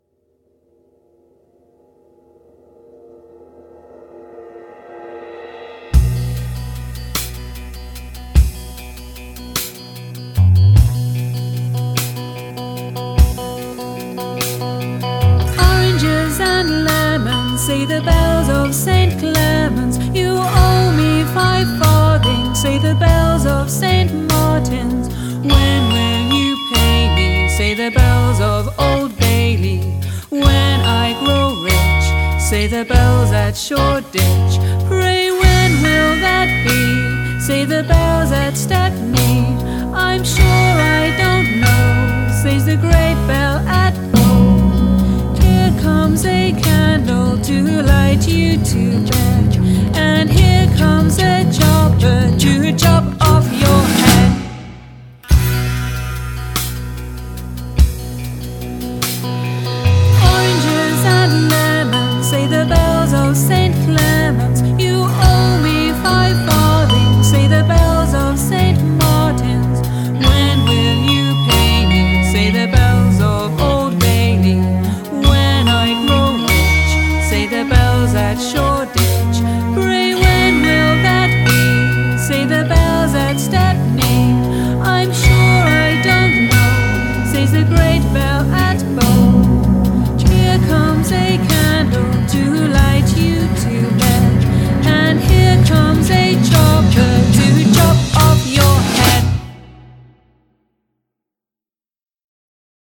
TraditionalSpooky